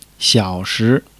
xiao3-shi2.mp3